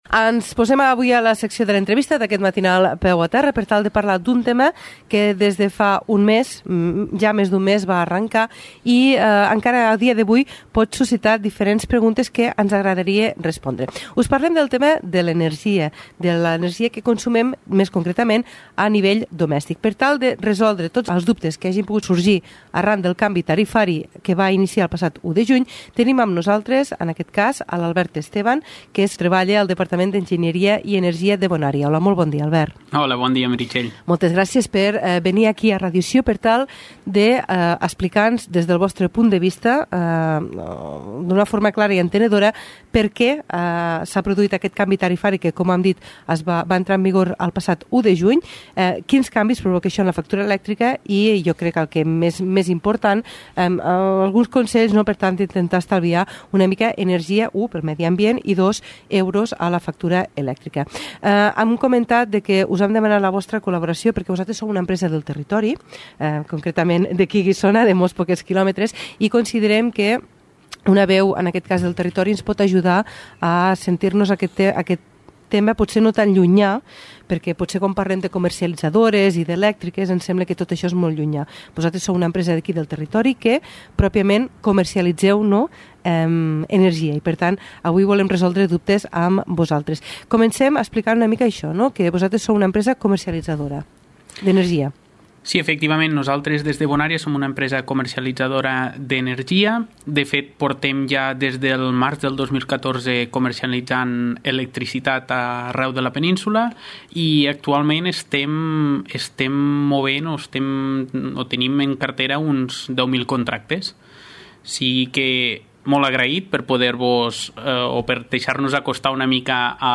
Entrevista a Radió Sió Agramunt per conèixer millor la nova facturació de la llum – O2 bonÀrea